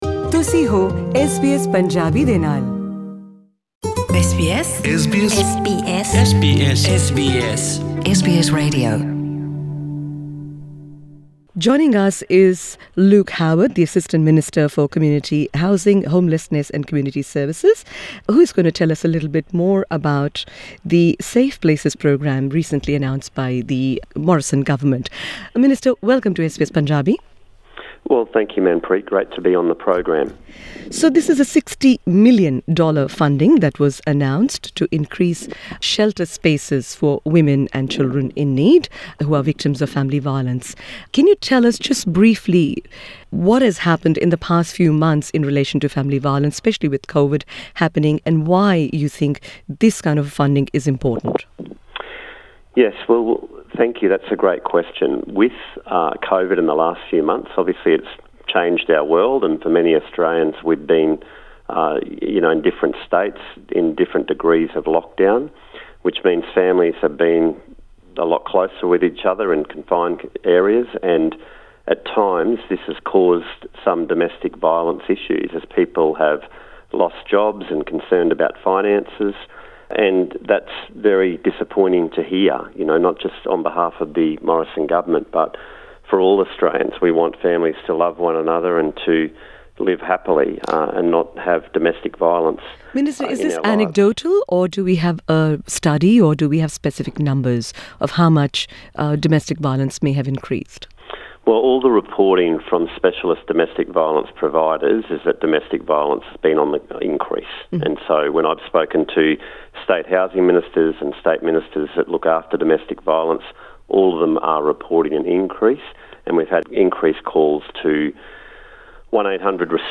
This grant will 'help women and children of Indian descent in Brisbane,’ he told SBS Punjabi.
(R) Luke Howarth, Assistant Minister for Community Housing, Homelessness and Social Services Source: Supplied